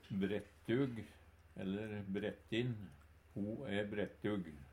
DIALEKTORD PÅ NORMERT NORSK brettug/brettin kresen/matvand Ubunde han-/hokj.